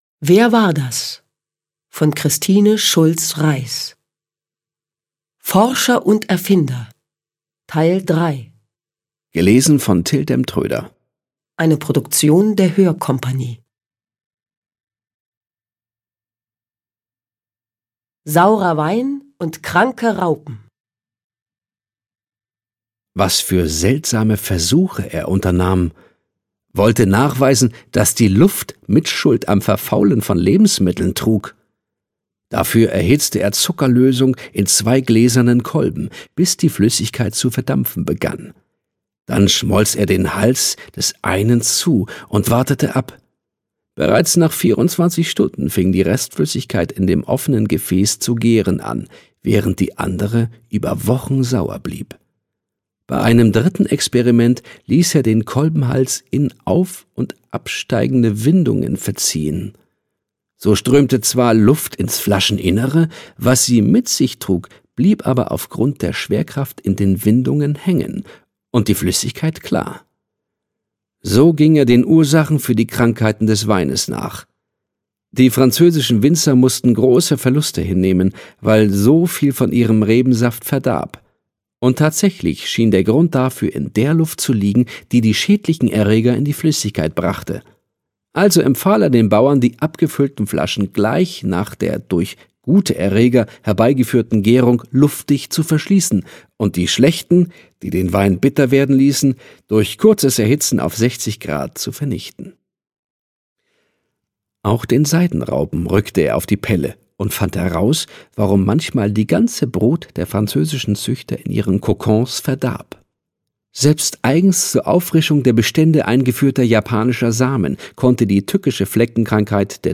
Till Demtrøder (Sprecher)